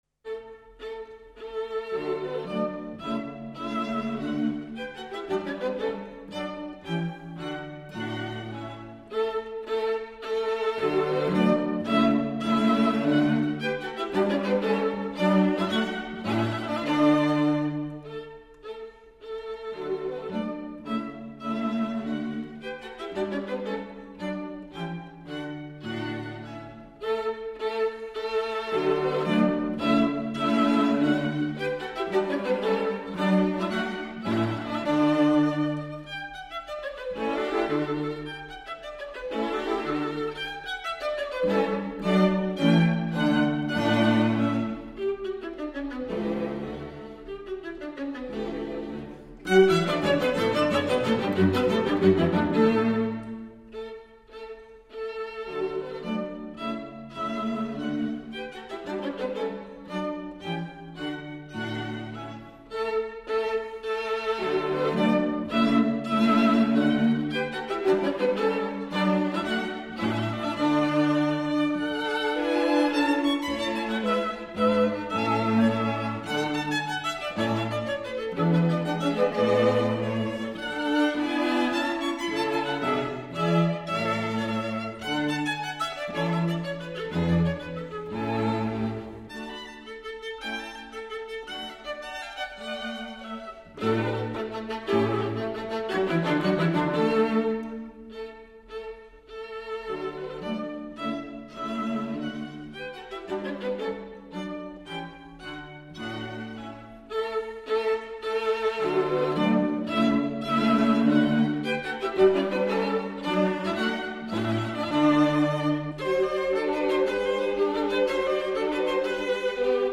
Andante grazioso